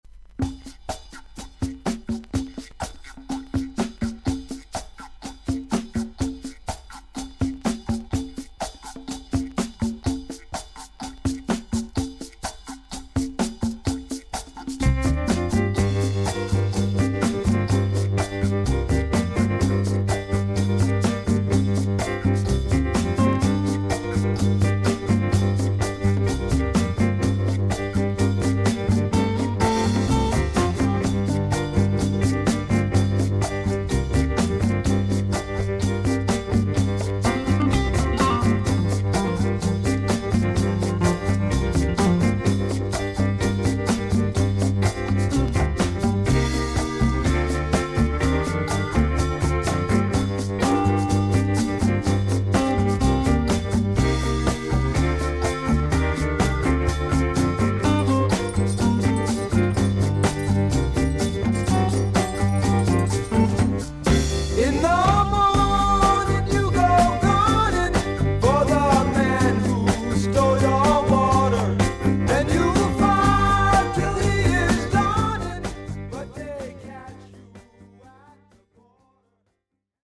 VG++〜VG+ 少々軽いパチノイズの箇所あり。クリアな音です。